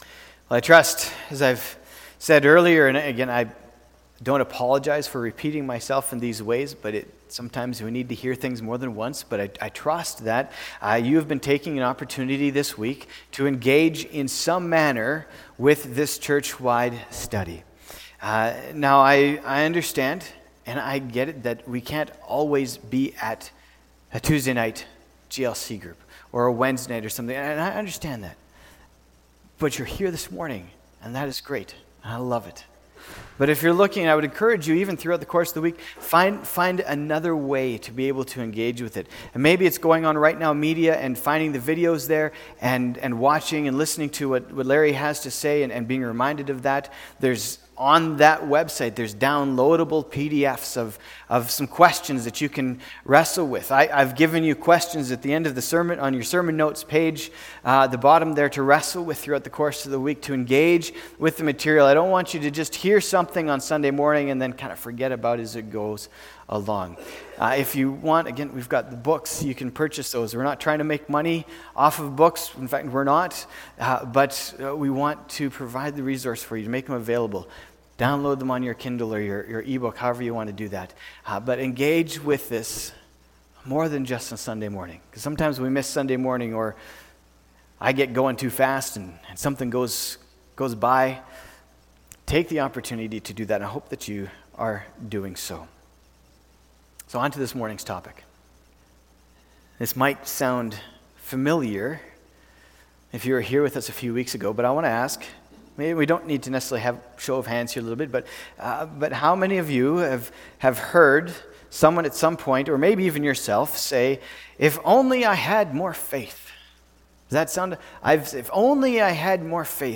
october-20-sermon.mp3